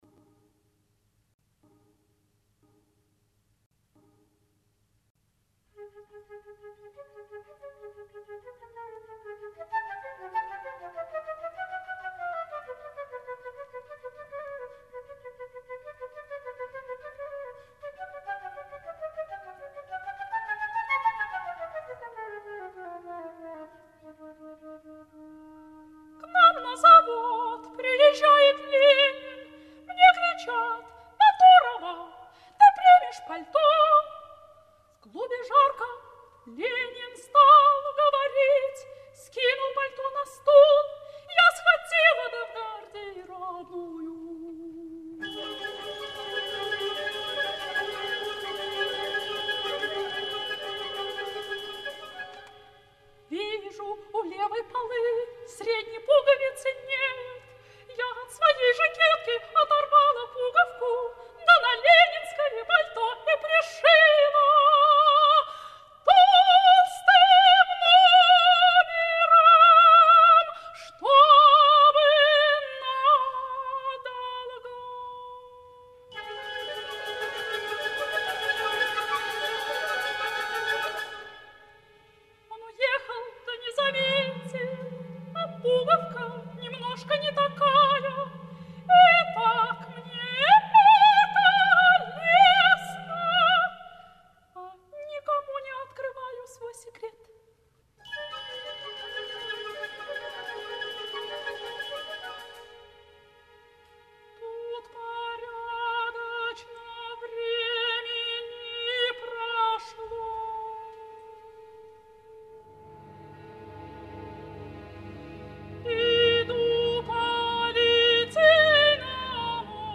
солистка